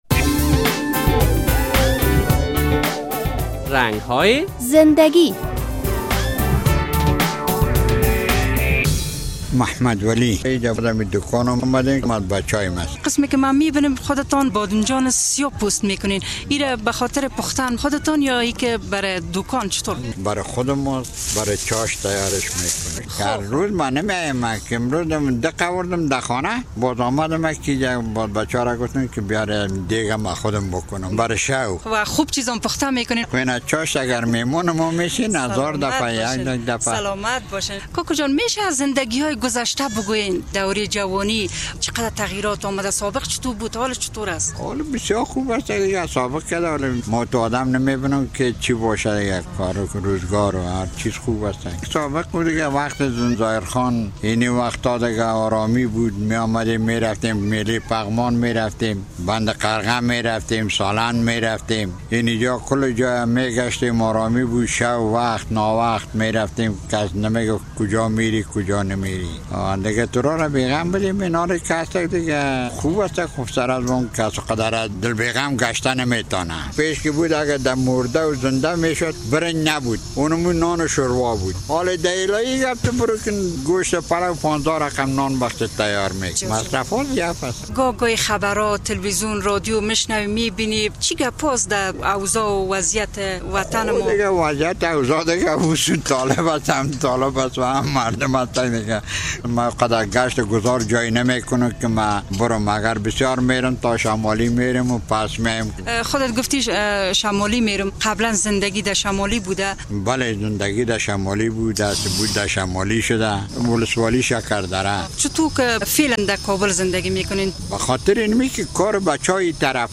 کهن سالان از روزگار آرام اوضاع افغانستان قصه های دارند و گاه گاهی پسران جوان خود را با داشته های سال های قبل از جنگ خوشحال می سازند. اما آیا چه آرزو های دارد در این برنامه با یک تن از ریش سفیدان مصاحبه کرده ایم: